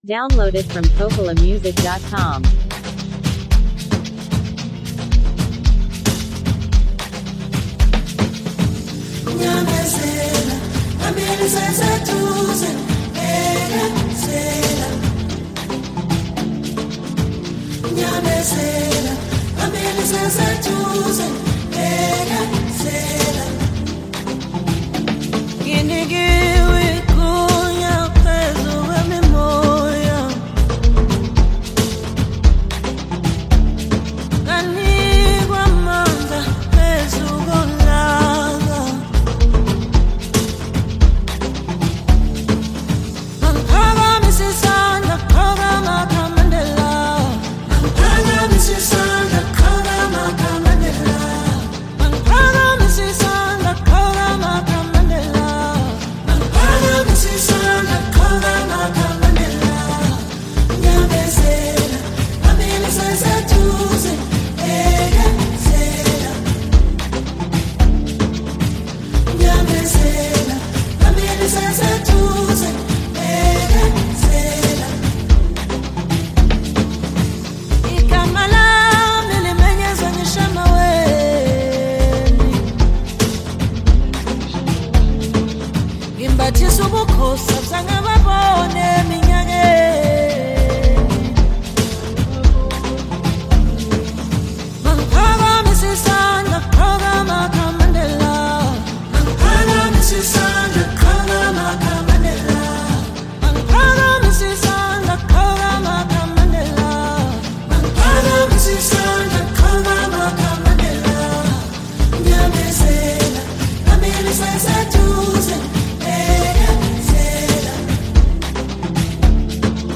smooth, expressive tone